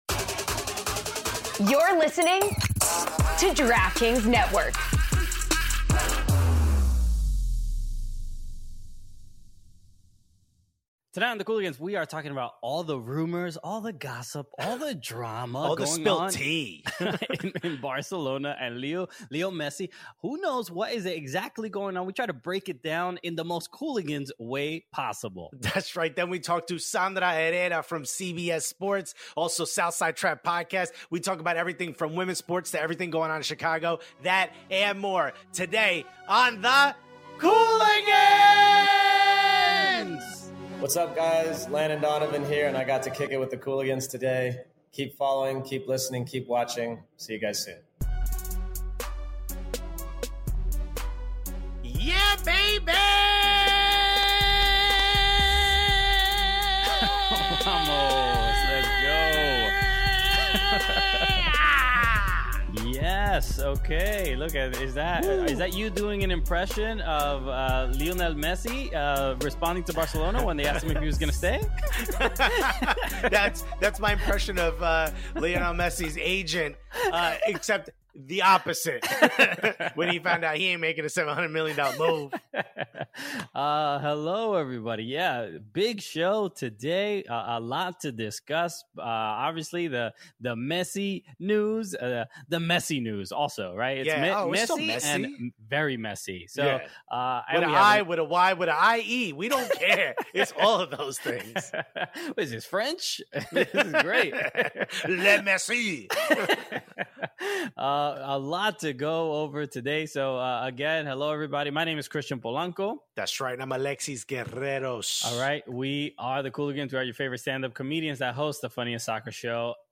Podcast Attacking Third: A CBS Sports Women's Soccer Podcast NWSLPA Interview